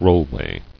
[roll·way]